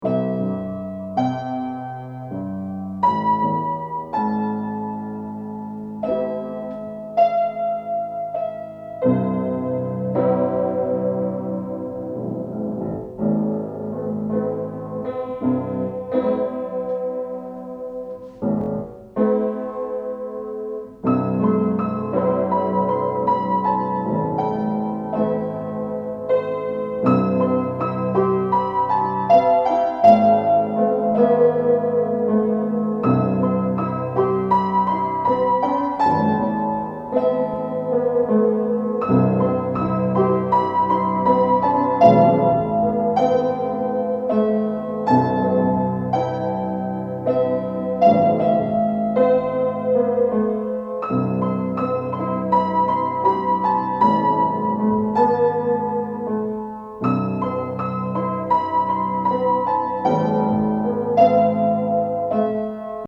Out of tune piano on the Isle of Skye
2 tracks of piano.
piano_layer_on_Skye_mix1_cutdown.mp3